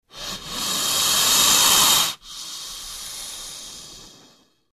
Звуки змей
Шипение вариант второй